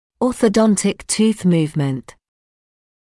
[ˌɔːθə’dɔntɪk tuːθ ‘muːvmənt][ˌо:сэ’донтик ту:с ‘му:вмэнт]ортодонтическое перемещение зубов/зуба